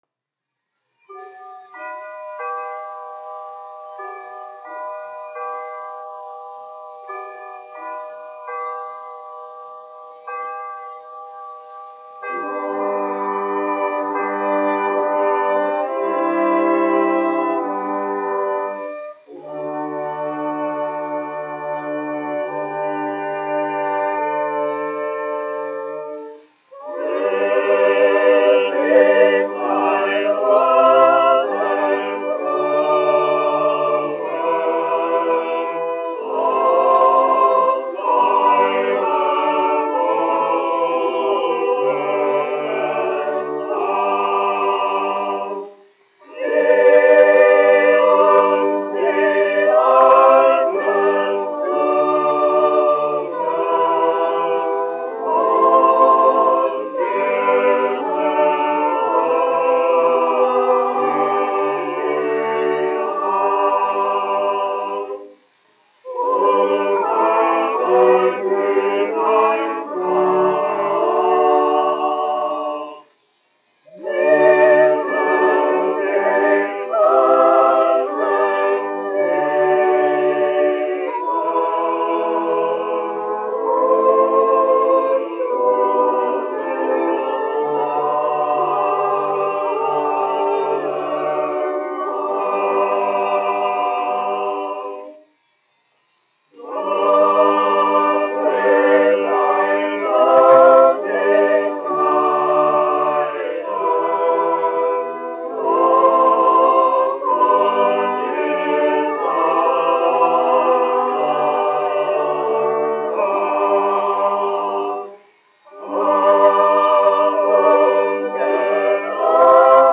Königliche Hofoper (Berlīne, Vācija) Koris, izpildītājs
1 skpl. : analogs, 78 apgr/min, mono ; 25 cm
Ziemassvētku mūzika
Kori (jauktie)
Skaņuplate